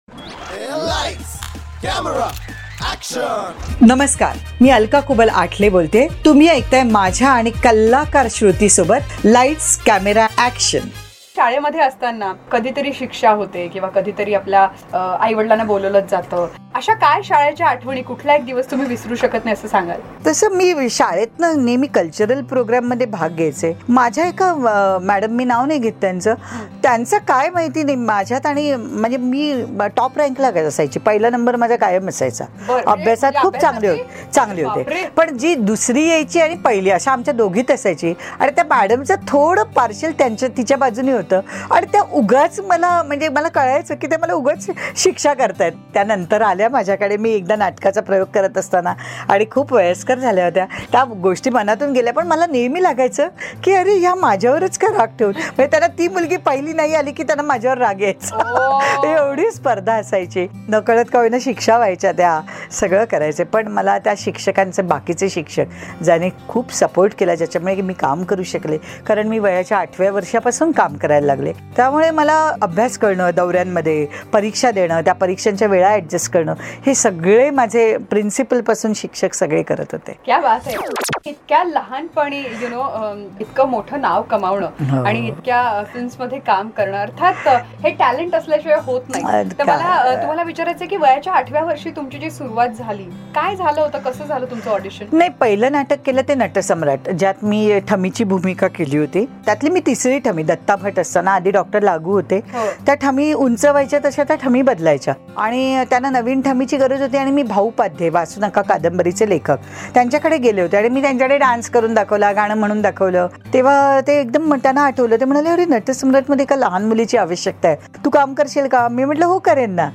CONVERSATION WITH ACTRESS ALKA KUBAL PART 2